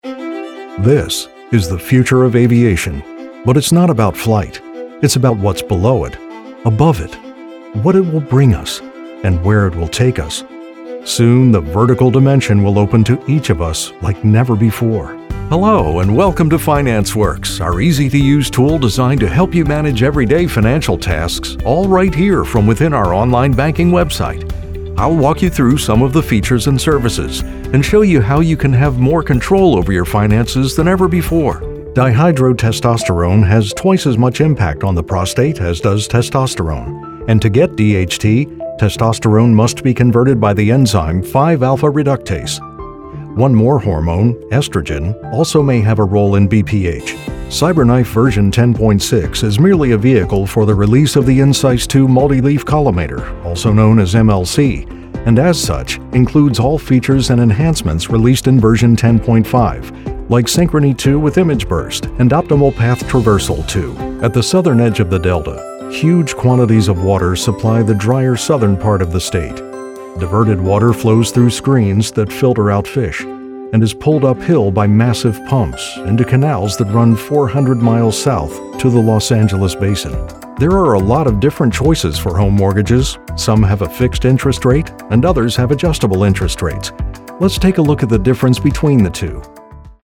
Veteran VO talent with warm, authoritative, clear and convincing voice.
Narration
My voice is best described as warm, articulate, friendly and authoritative.